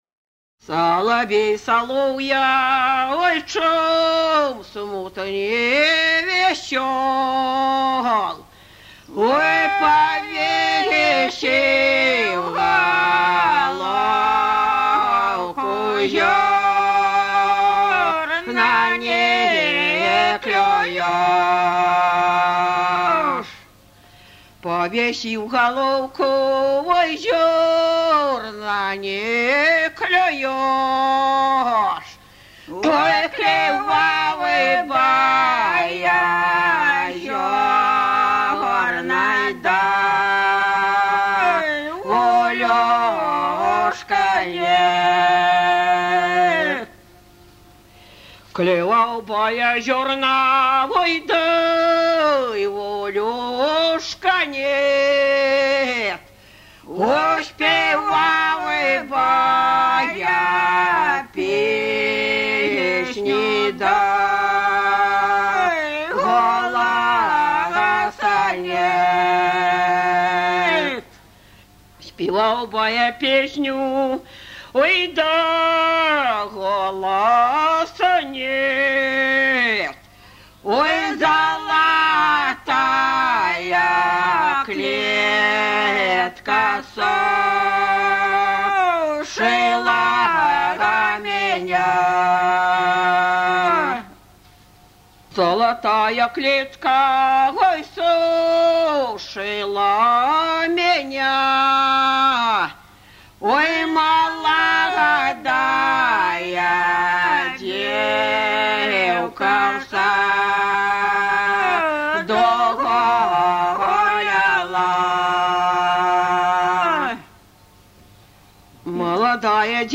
В местных песнях распространен характерный прием гукания — глиссандо голосами (чаще всего восходящее) в окончаниях песенных строк или куплетов.